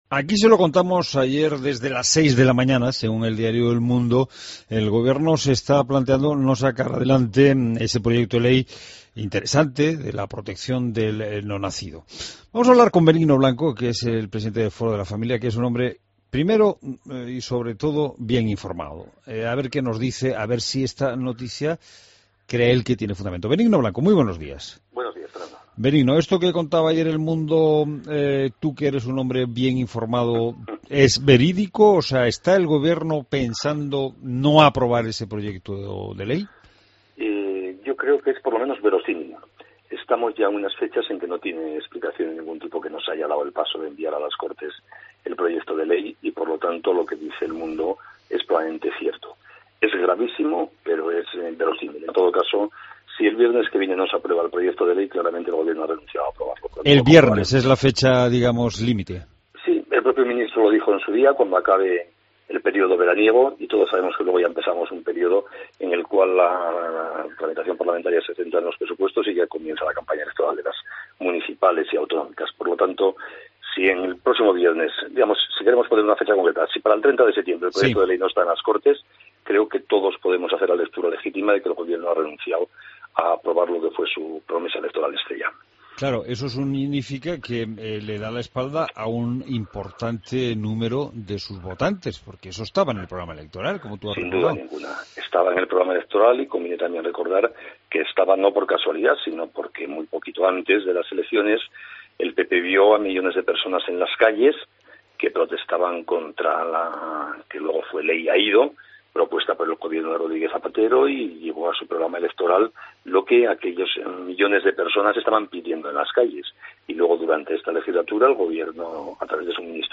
Entrevista a Benigno Blanco en La Mañana Fin de Semana